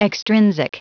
Prononciation du mot extrinsic en anglais (fichier audio)
Prononciation du mot : extrinsic